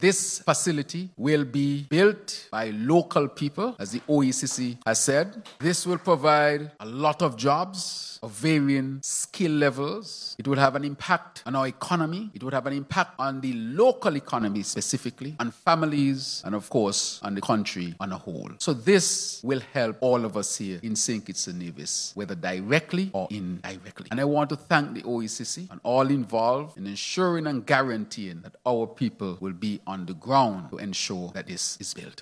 A ceremony was held at the St. Kitts Marriott Resort on Friday, February 7th to unveil the designs in 3D version of the new “Climate-Smart Joseph N. France General Hospital”.
Hon. Dr. Terrance Drew.